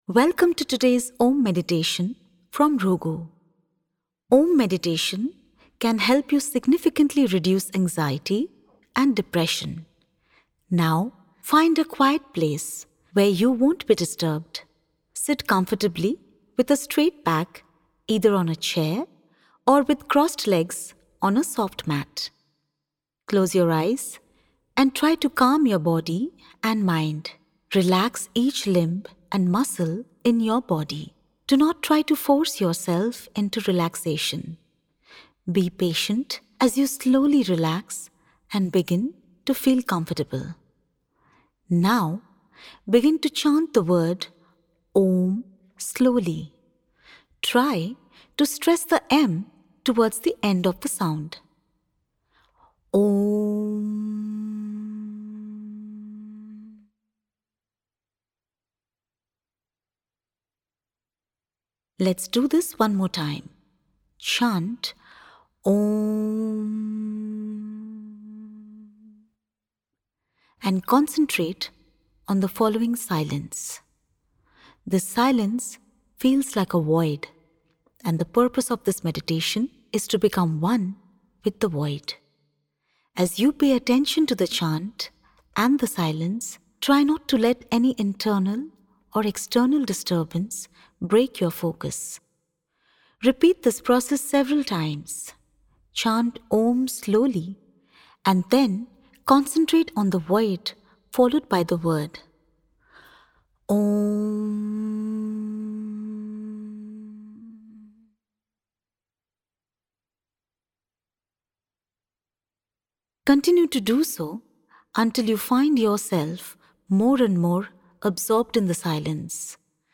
Om Meditation is the technique of chanting the sacred word “Om” that helps meditators relax their minds, while establishing a healthy balance between their inner and outer worlds(1).